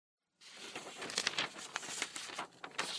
Free Foley sound effect: Paper Rustle.
Paper Rustle
025_paper_rustle.mp3